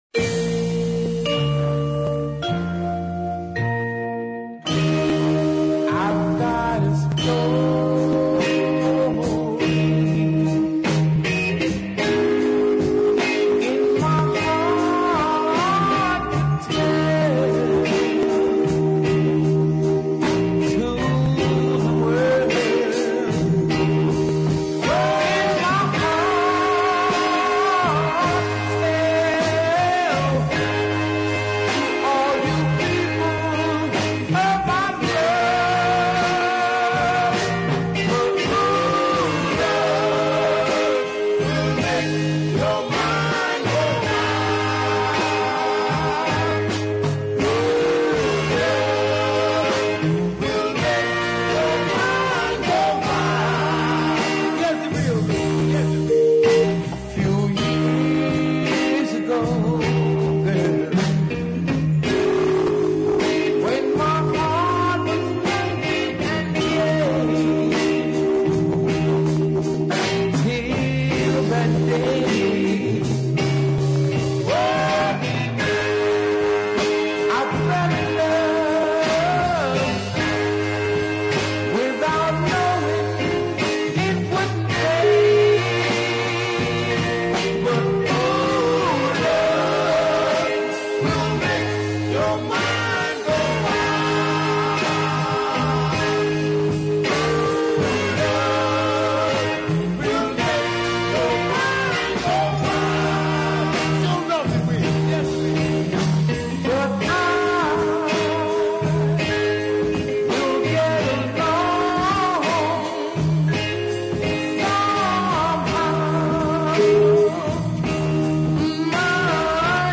has an easy plodding rhythm